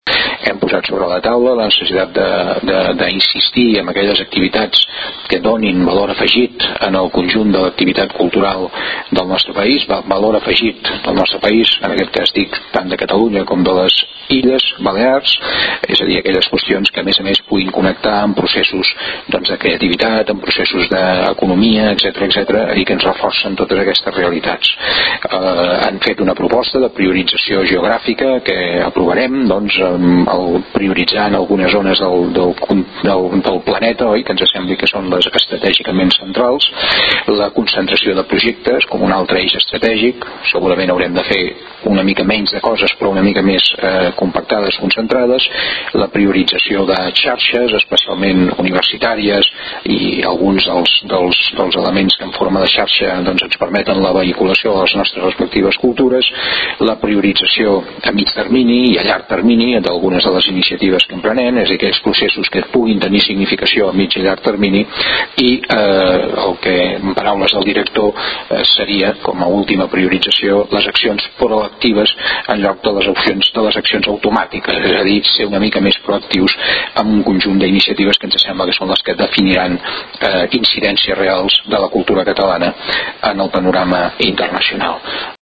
Àudio: El conseller Mascarell explica les conclusions de la primera reunió del Consell de Direcció de l'IRL